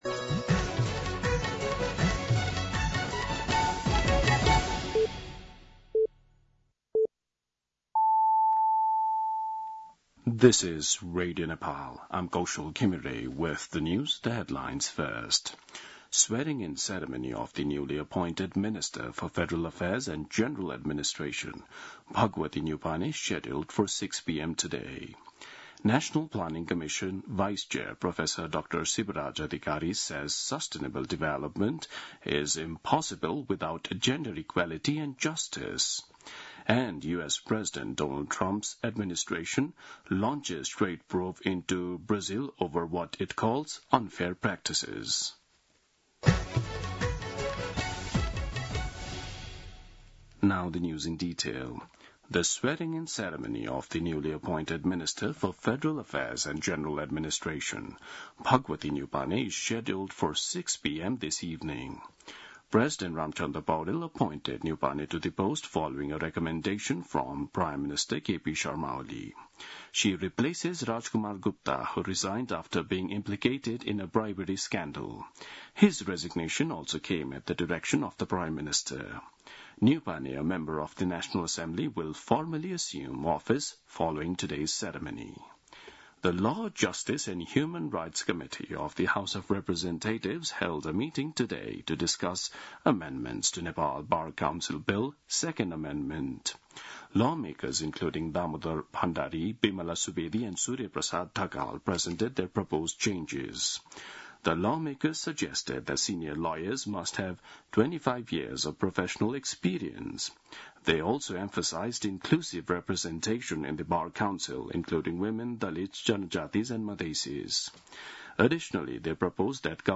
दिउँसो २ बजेको अङ्ग्रेजी समाचार : ३२ असार , २०८२
2-pm-English-News-3-32.mp3